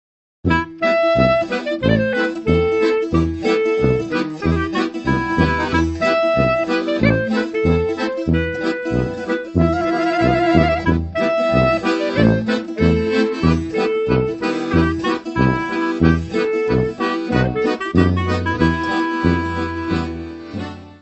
traditional Yiddish music
violino
clarinete
saxofone
acordeão
tuba
tapan, darabukka.
Music Category/Genre:  World and Traditional Music